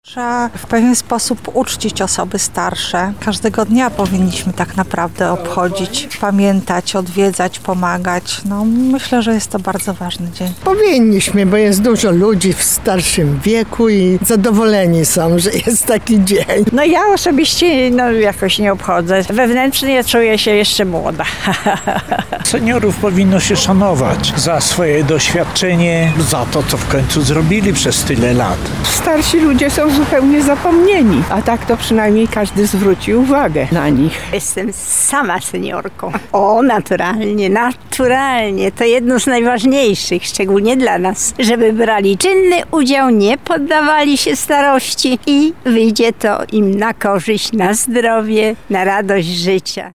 Z tej okazji przeprowadziliśmy sondę uliczną wśród najstarszych obywateli Lublina.
SONDA